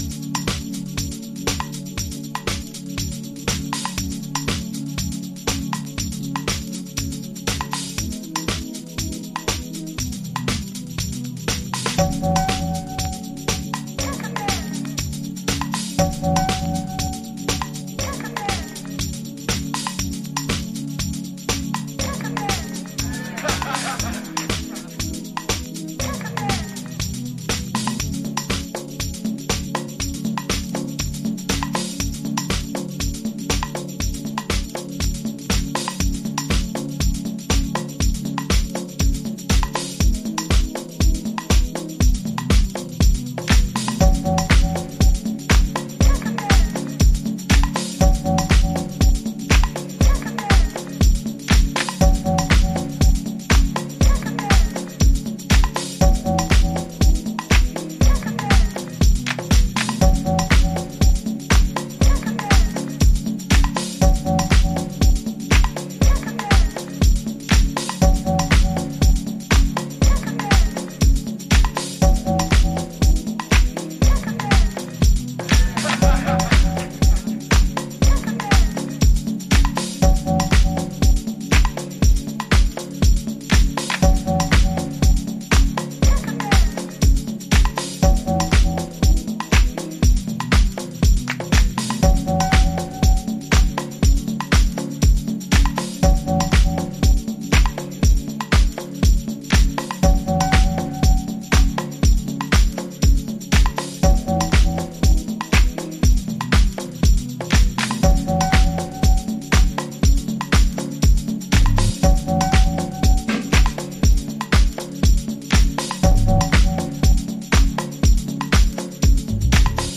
サンプリングメインでつくり上げられた、粒子浮き立つRAW HOUSE TRACKS。